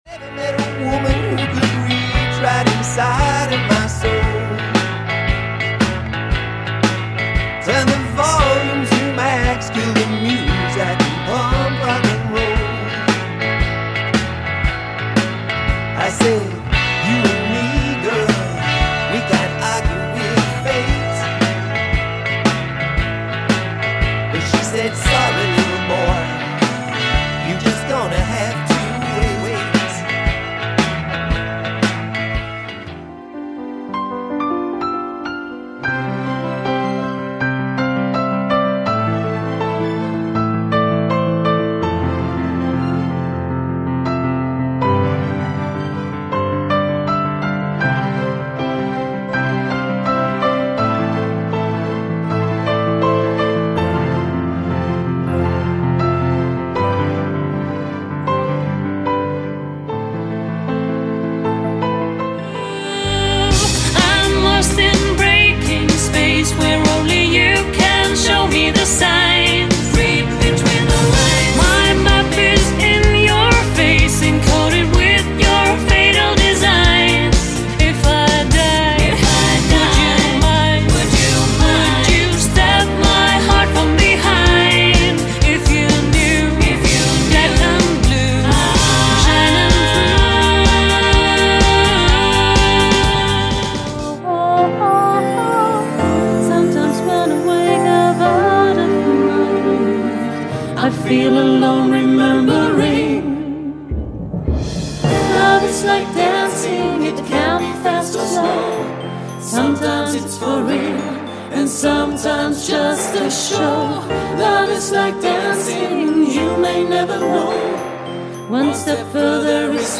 Recap of all HCSC 2017 songs in draw order